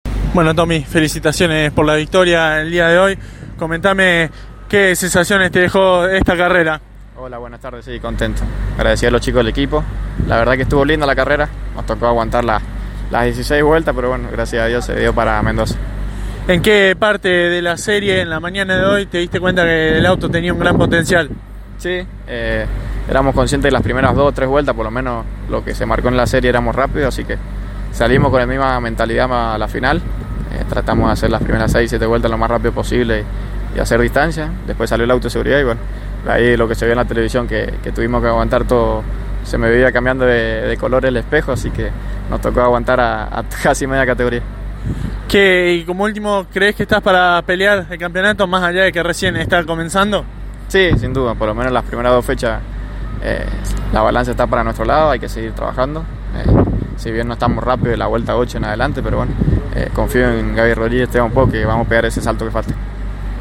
Por ello, tras concluida la final de Clase 2, dialogó con los protagonistas que aquí podrás escuchar.